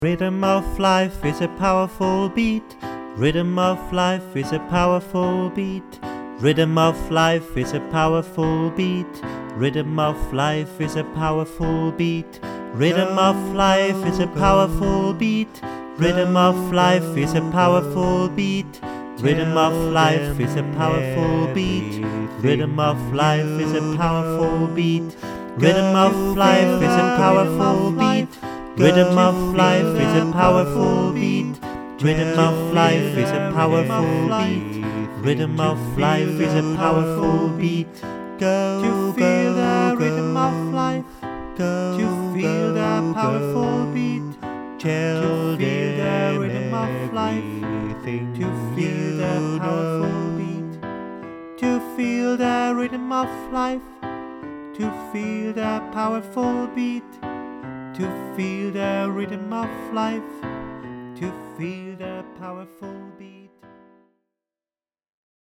Kanon gesungen
rhythm_of_life_gesungen.mp3